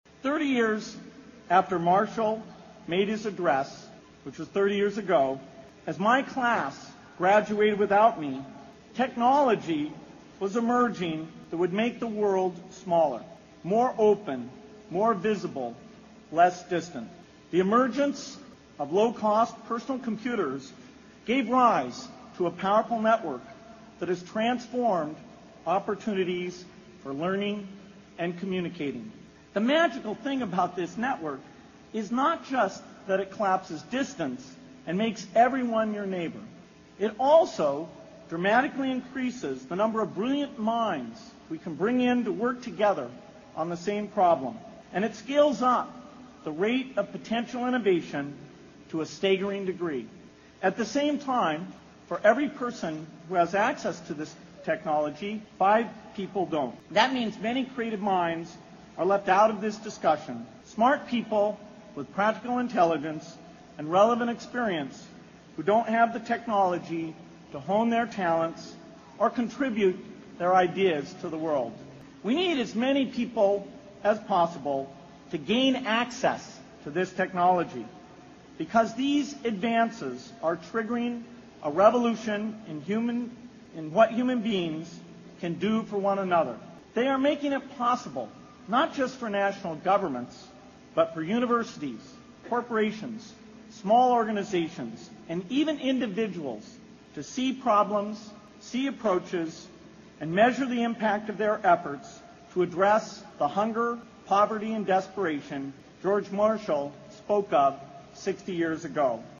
名校励志英语演讲 35:如何解决这个世界上最严重的不平等?